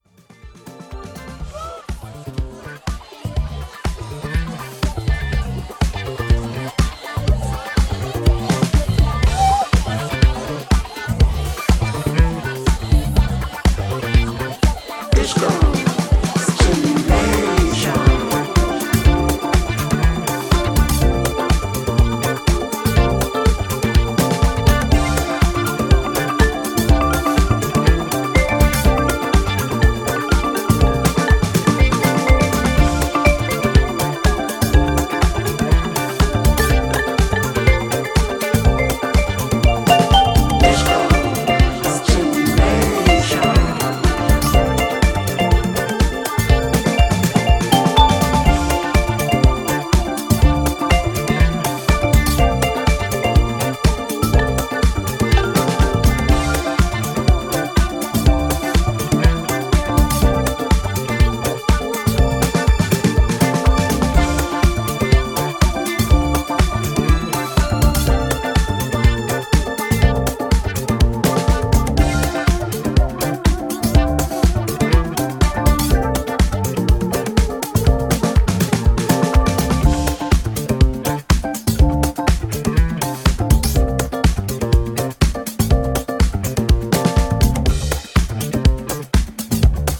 スペーシーなシンセが躍動するディスコブギー路線のオリジナル曲